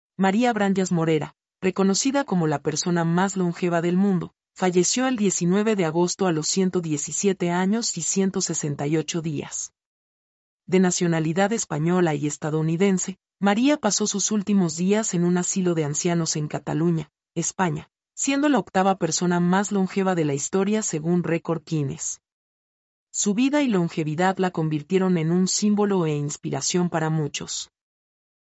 mp3-output-ttsfreedotcom-87-1.mp3